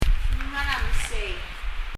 meséi 　　　　[mɛsei]　　　　タロ畑　　　taro patch